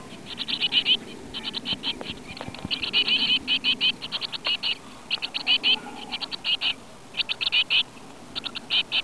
Una Cinciarella ha fatto il nido in una profonda cavità in un muro del pollaio.I suoi piccoli emettono un caratteristico cinguettio quando i genitori entrano nel nido.
Cinguettio (100 KB) oppure scaricali in formato compresso (.zip)
cinciarellenelnido.wav